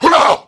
pain100_1.wav